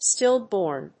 音節stíll・bórn 発音記号・読み方
/ˈstɪˈlbɔrn(米国英語), ˈstɪˈlbɔ:rn(英国英語)/